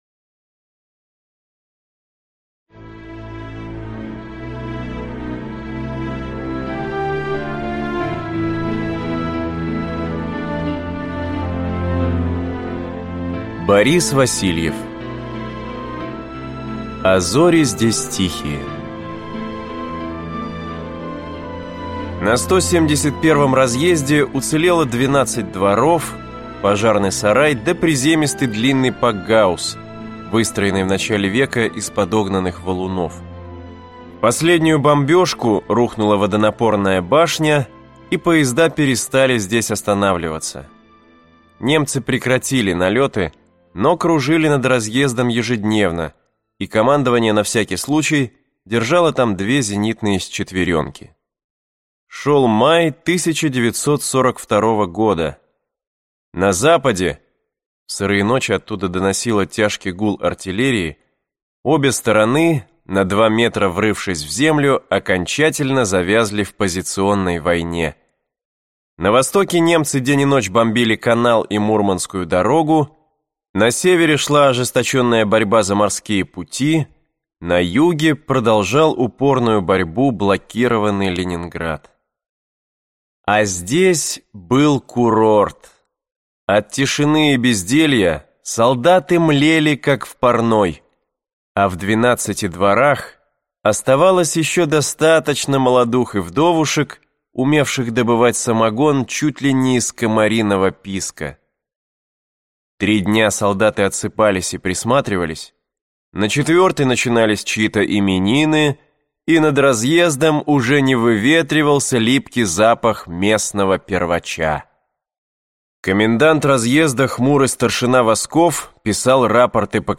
Аудиокнига А зори здесь тихие… Завтра была война | Библиотека аудиокниг